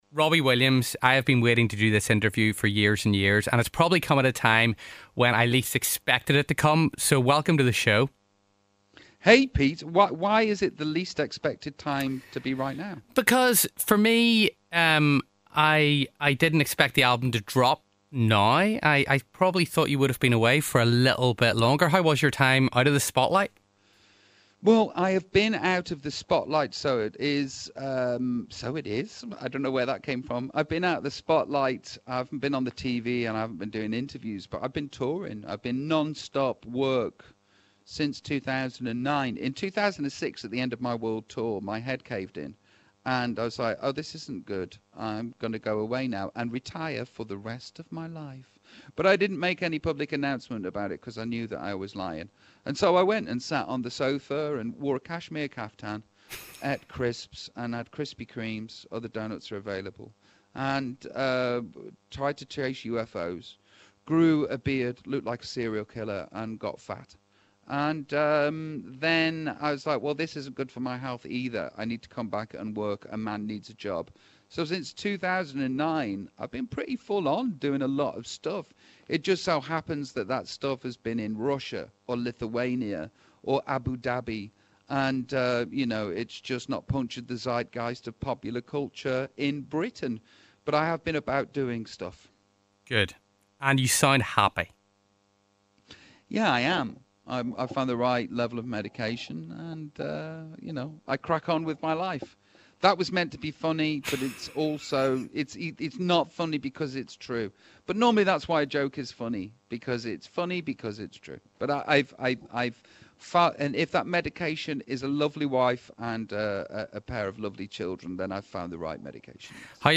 Robbie Williams in conversation